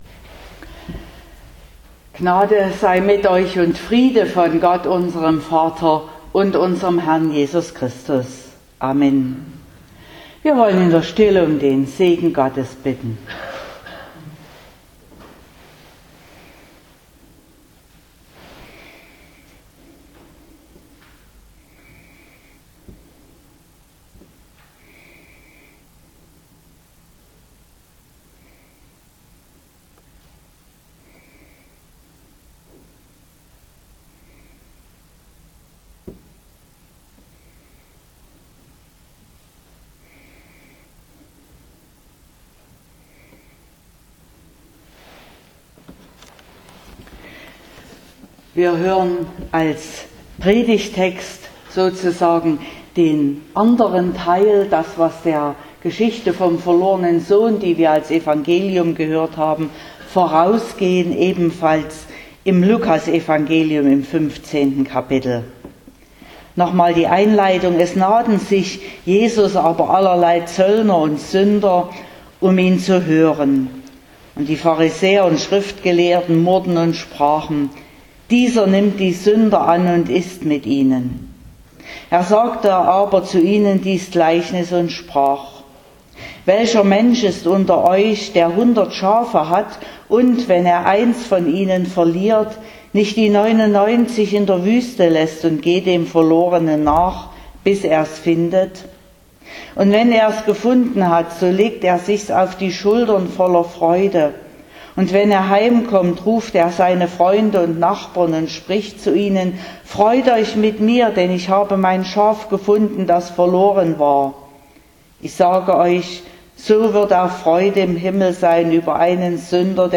20.06.2021 – Gottesdienst
Predigt und Aufzeichnungen